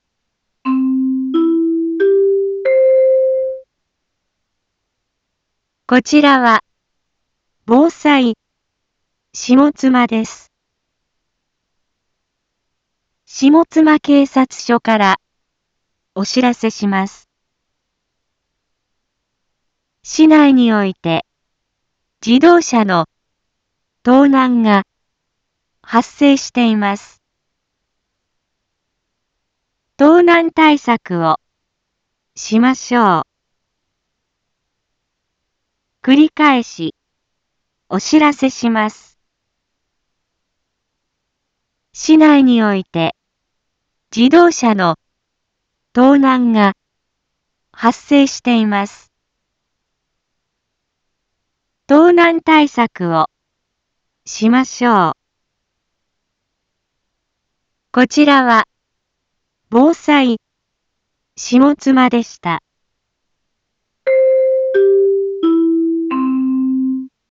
一般放送情報
Back Home 一般放送情報 音声放送 再生 一般放送情報 登録日時：2022-01-07 12:31:11 タイトル：自動車盗難への警戒について インフォメーション：こちらは、防災下妻です。